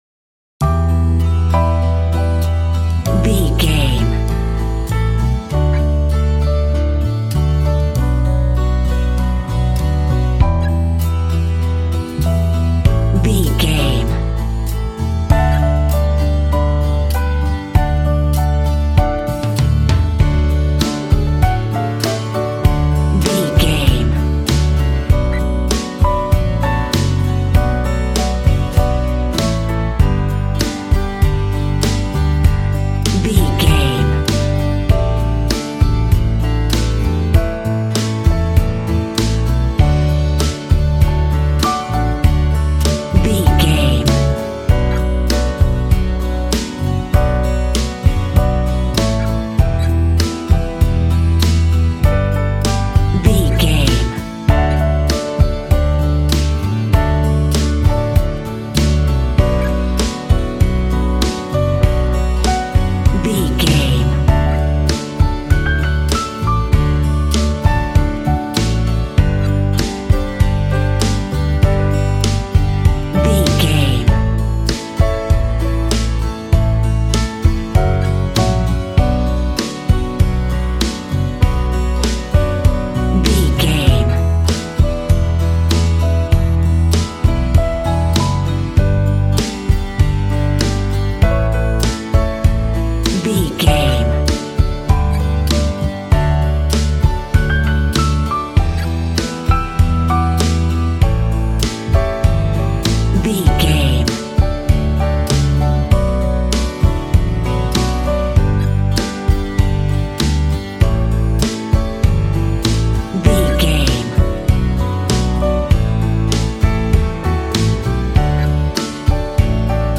Ionian/Major
Fast
bouncy
double bass
drums
acoustic guitar